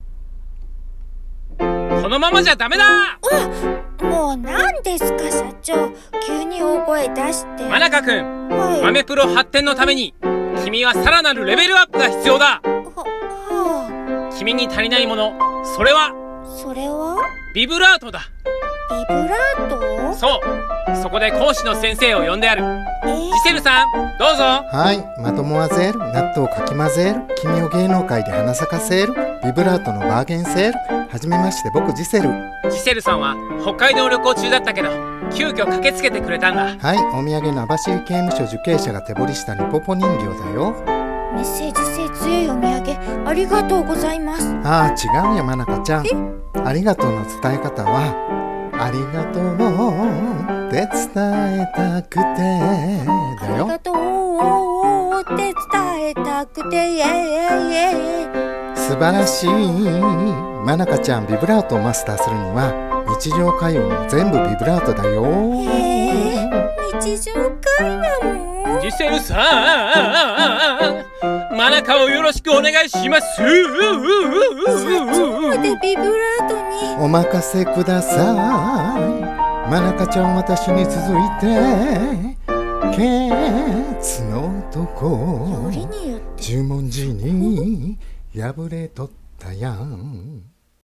コラボ用声劇台本